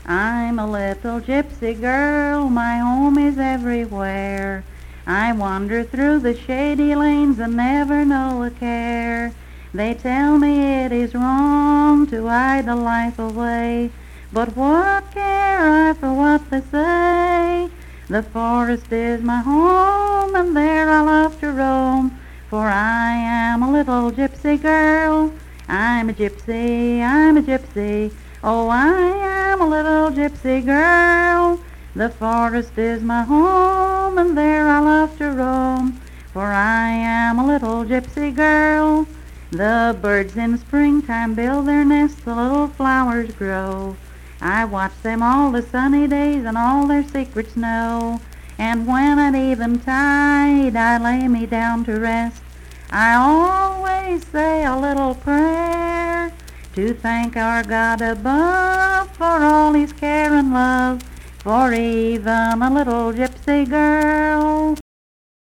Unaccompanied vocal music performance
Verse-refrain 2d(4) & Rd(4).
Voice (sung)